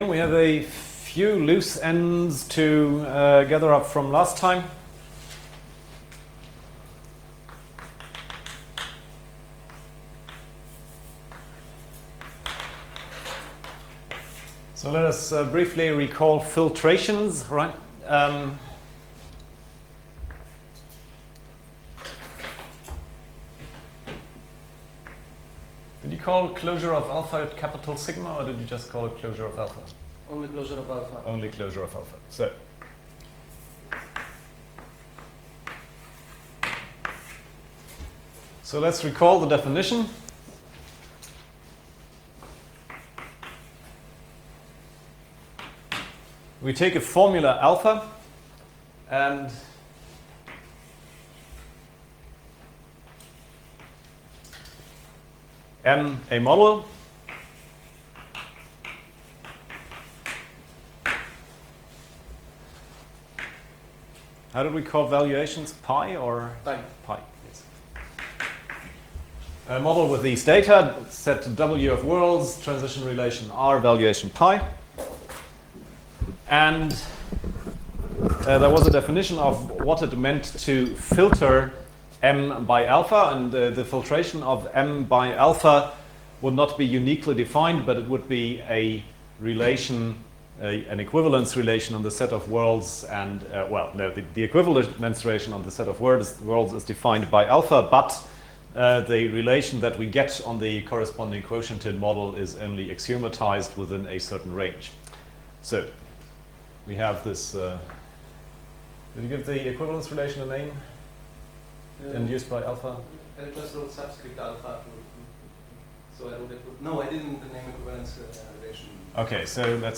Vorlesungsaufzeichnungen am Department Informatik